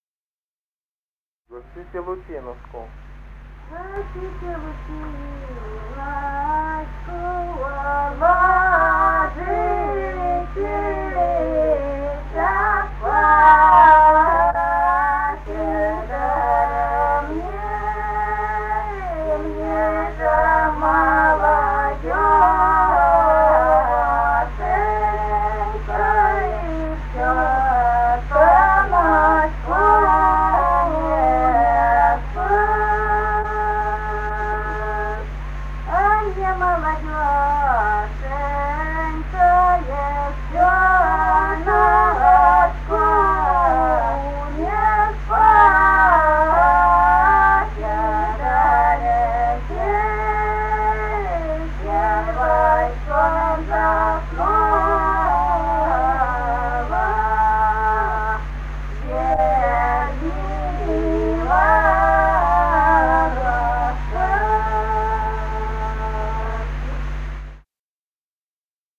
Русские народные песни Красноярского края.